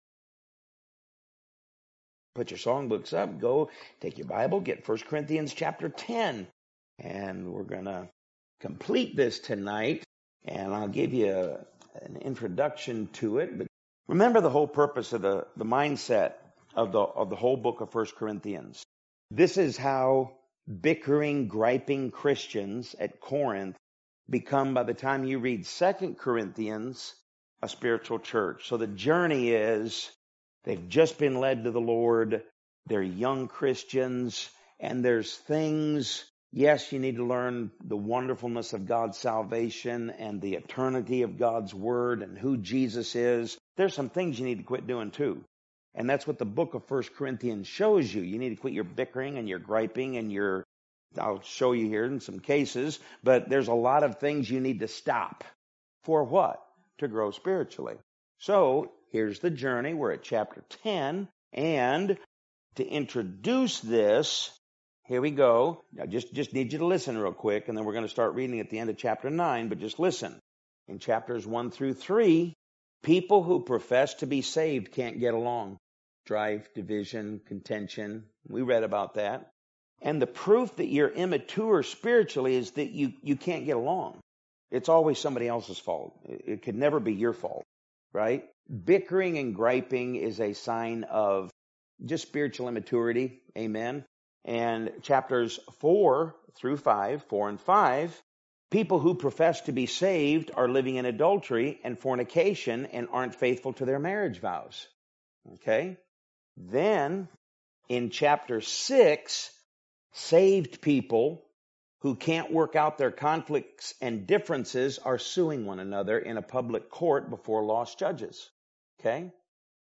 Podcast (sermon-podcast): Play in new window | Download